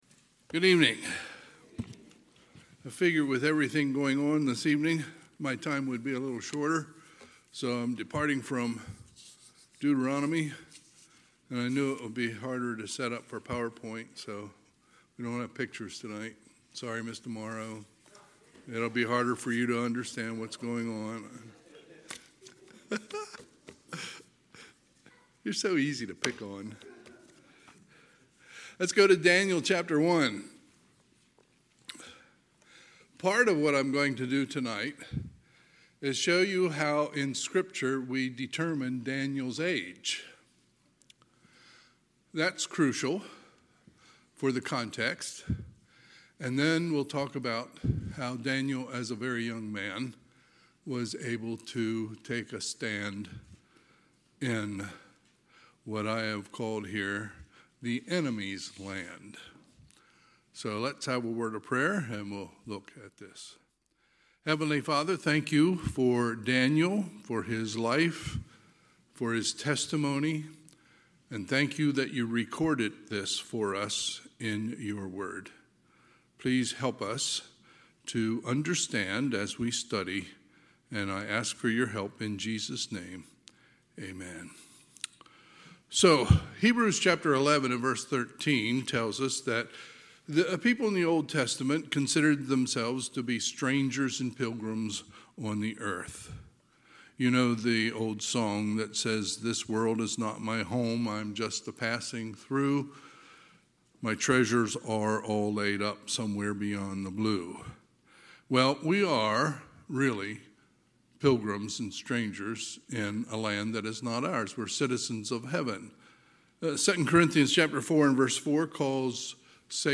Sunday, February 15, 2026 – Sunday PM
Sermons